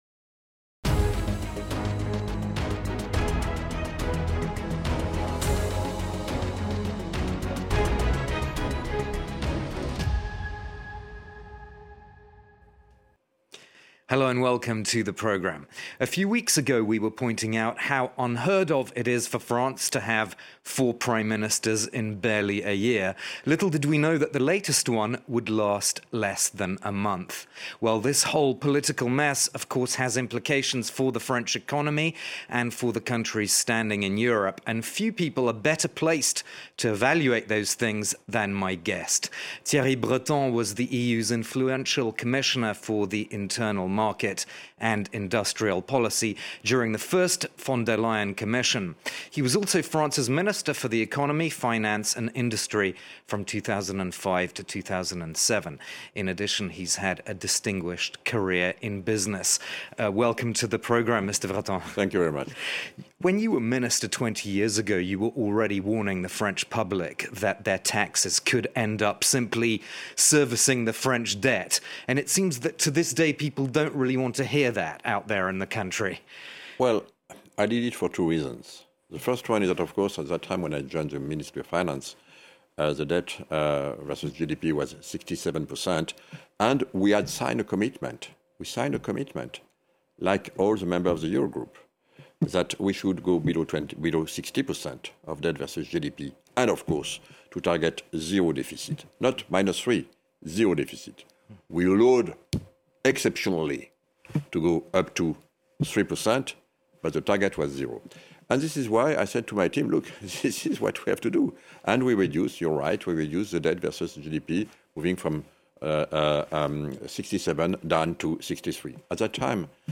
As political and economic turmoil continues in France, we speak to the influential former EU Commissioner for the Internal Market, Thierry Breton. A former minister of economy and finance in the French government, Breton gives us his reading of the debt situation in France, as well as of France’s current standing in the European Union.